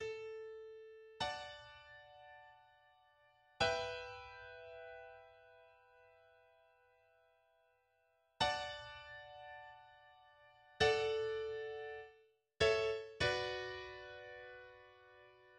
A triumphant chorale breaks forth but dissolves into a return of the tragic material of the opening of the movement.